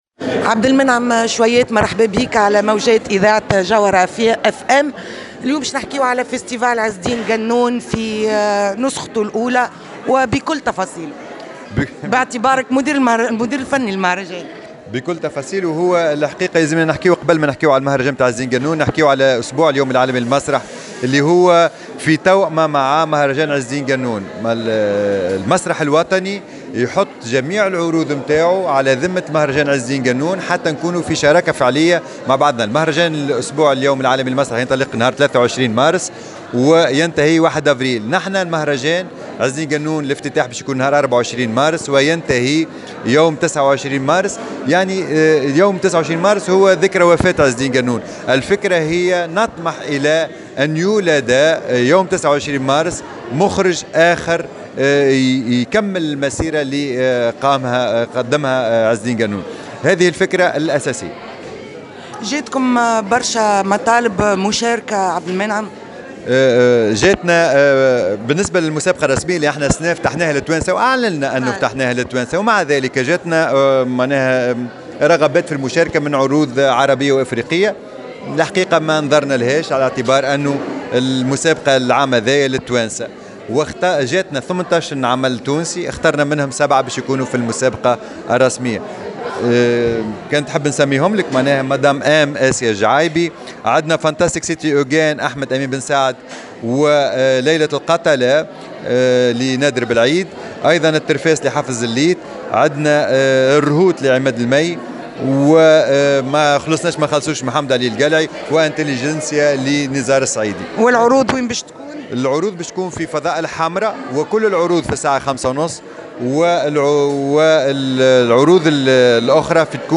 7 عروض مسرحية تتسابق على جوائز مهرجان عز الدين قنون للمسرح (تصريح)